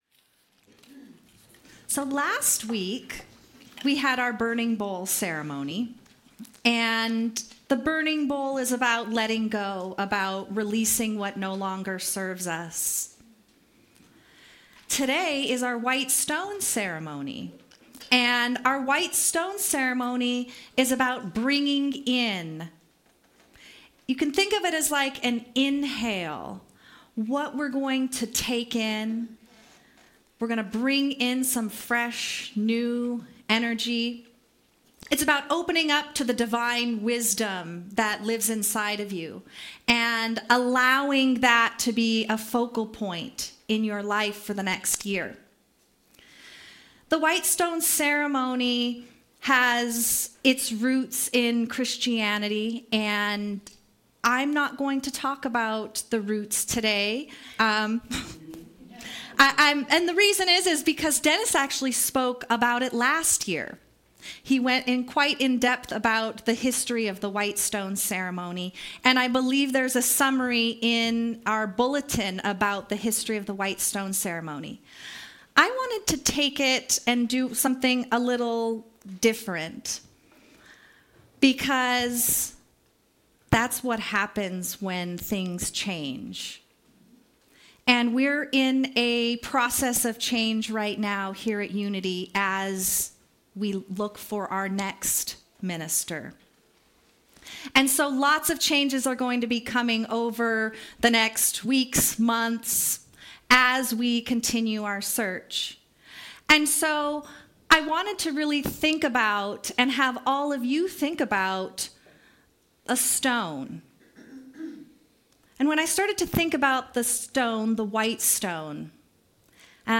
The audio recording (below the video clip) is an abbreviation of the service. It includes the Lesson, Meditation, and Featured Song.